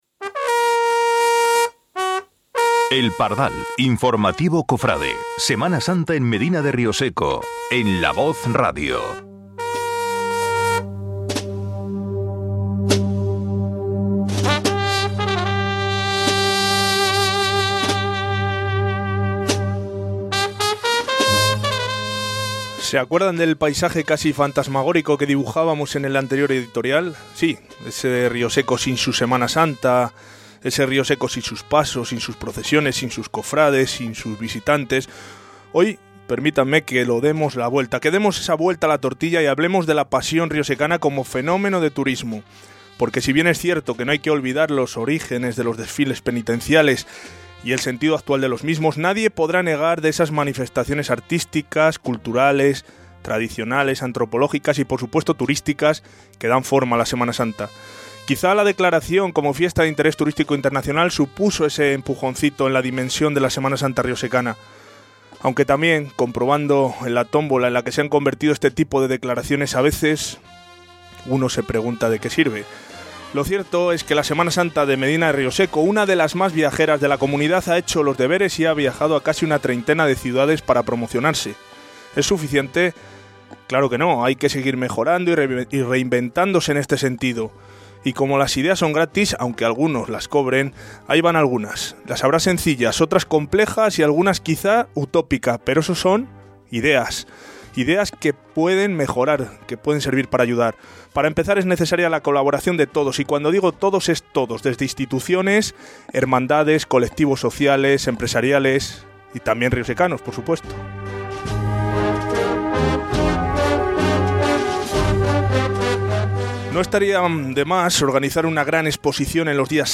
Muchos invitados han pasado por los estudios de La Voz de Rioseco para contra su trabajo y sus experiencias de la Semana Santa riosecana.
Han aflorado recuerdos, emociones y vivencias en una interesante entrevista que también ha tenido momentos simpáticos.